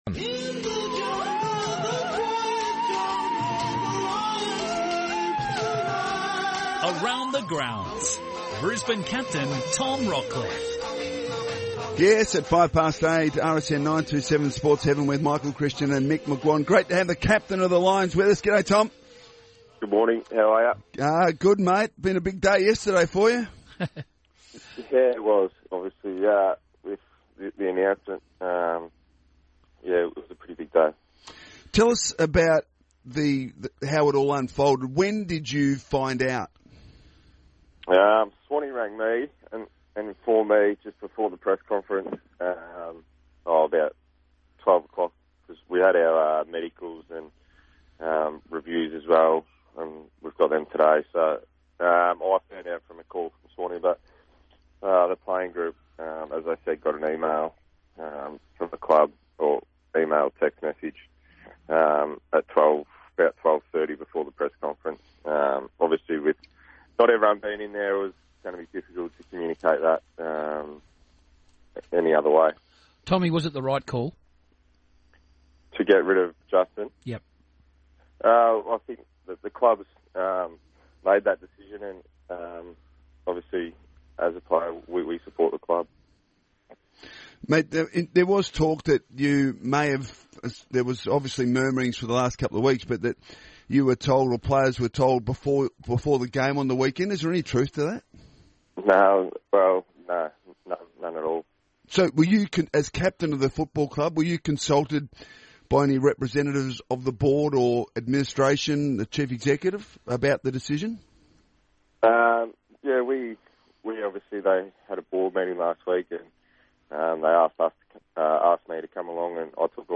Tom Rockliff spoke to the RSN team this morning after yesterday's events.